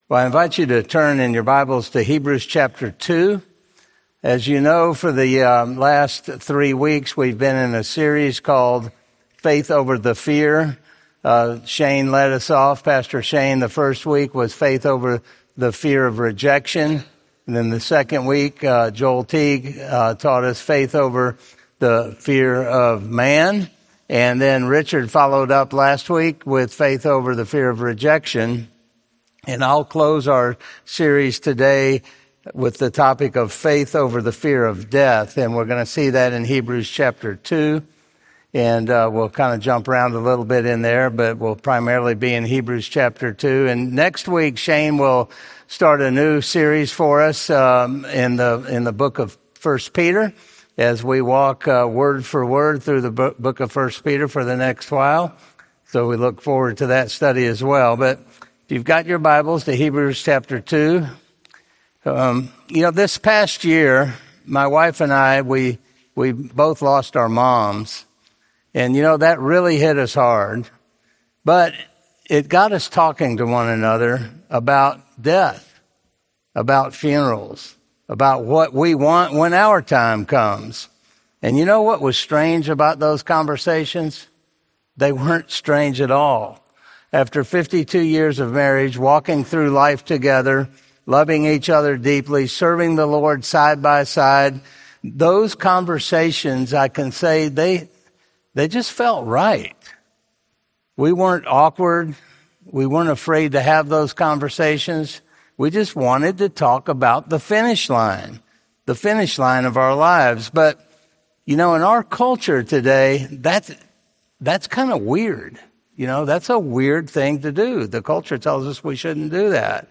Series: Faith Over Fear, Sunday Sermons